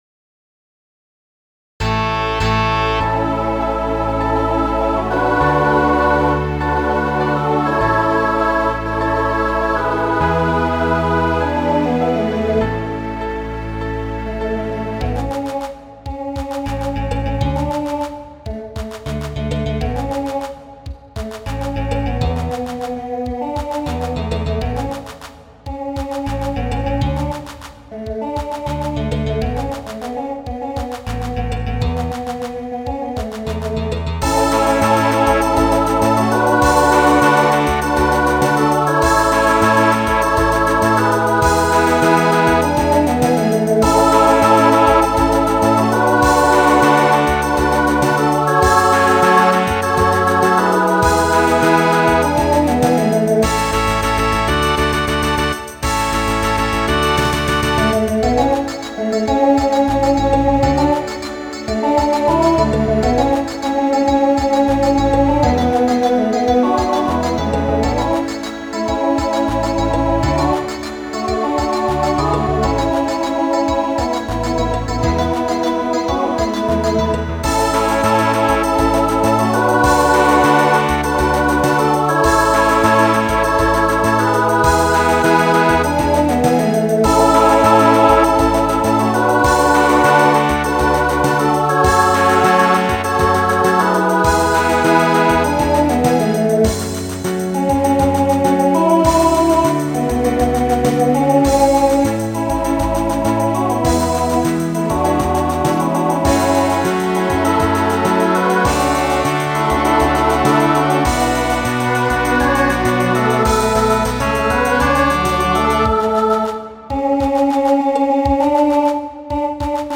SSA/TTB
Voicing Mixed Instrumental combo Genre Pop/Dance , Rock